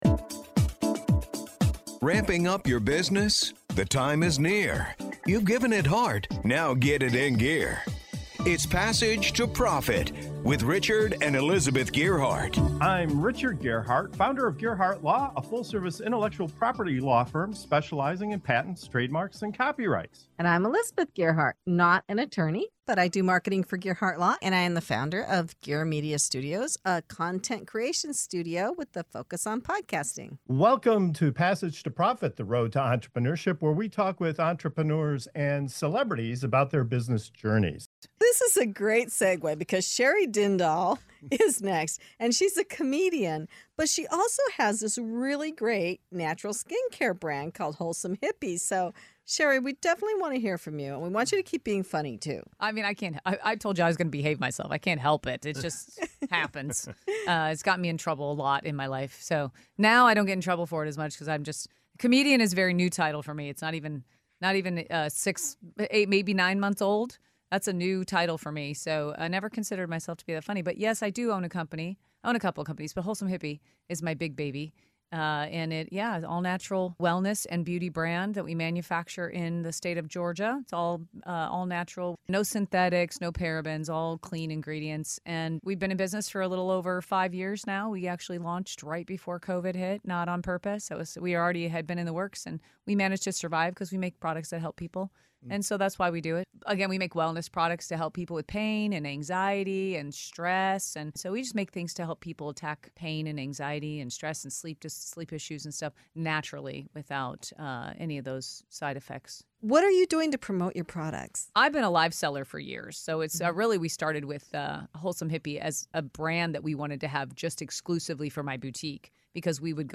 In this lively interview